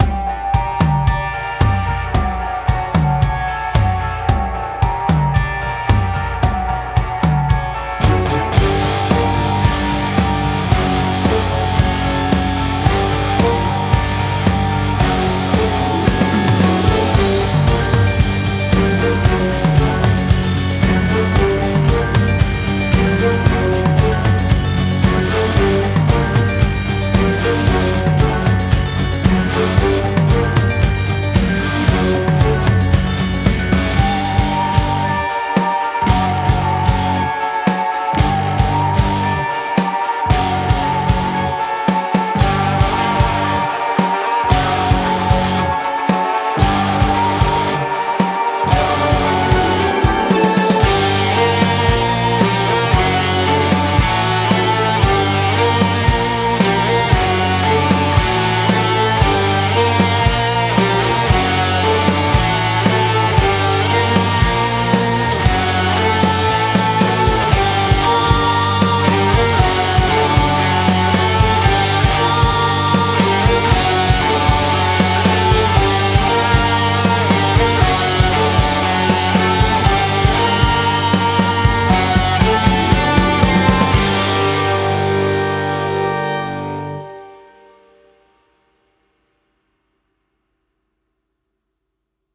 Genre: production, rock.